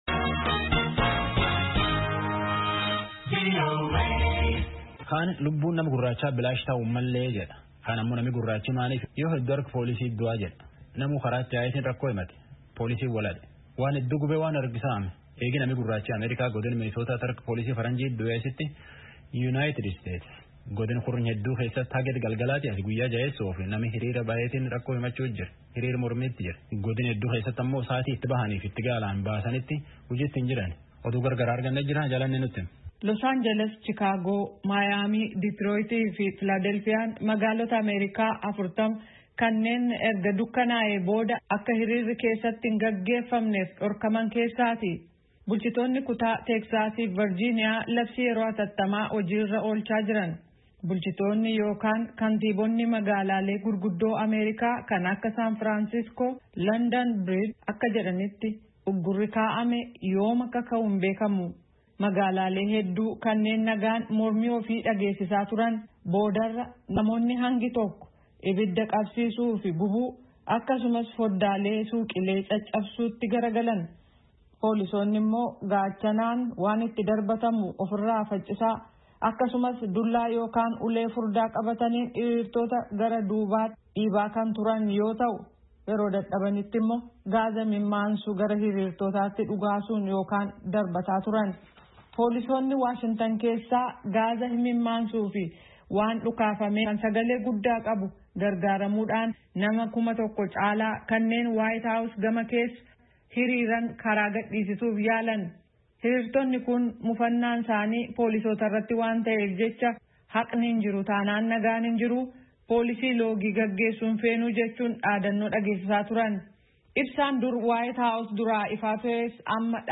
Gabaasaa guutuu caqasaa